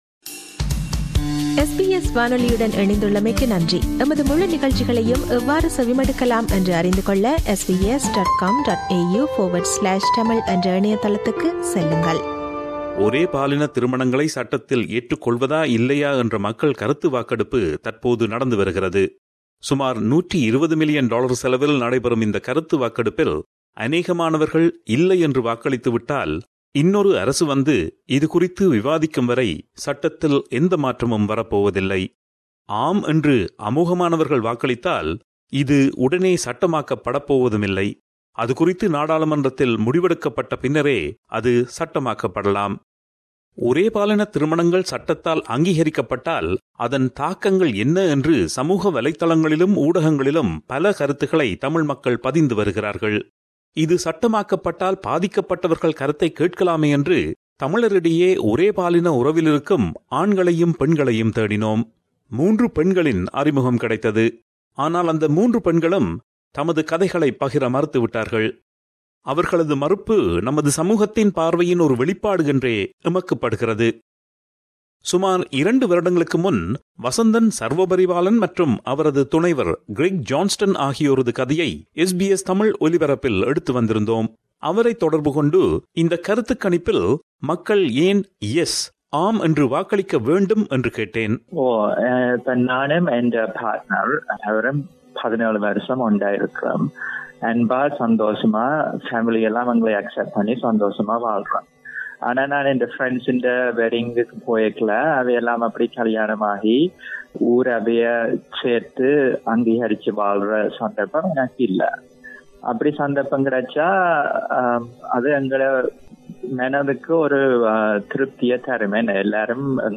ஒரே பாலினத் திருமணங்களை சட்டத்தில் ஏற்றுக் கொள்வதா இல்லையா என்ற மக்கள் கருத்து வாக்கெடுப்பு தற்போது நடந்து வருகிறது. ஒரே பாலின உறவிலிருக்கும் தமிழர் இருவர் ஒரே பாலினத் திருமணங்கள் சட்டமாக்கப்படுவதை ஏன் விரும்புகிறார்கள் என்பதைப் பகிர்கிறார்கள்.